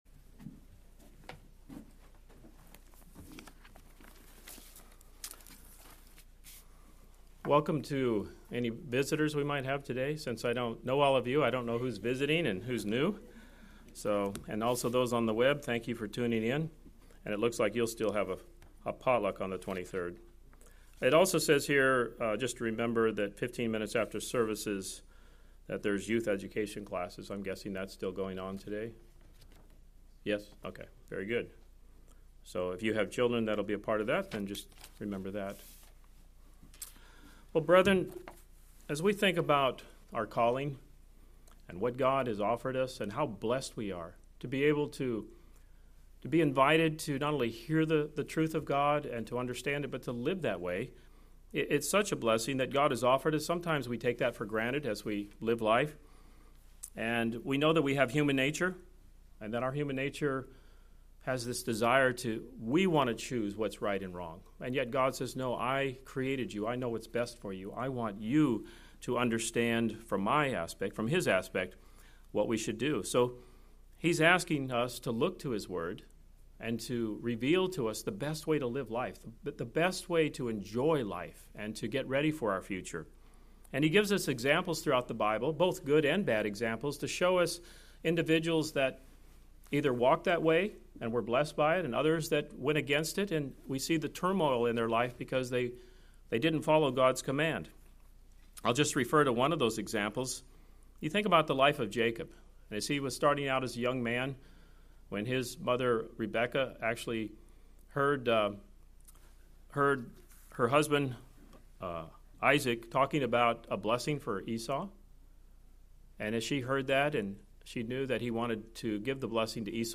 Given in St. Petersburg, FL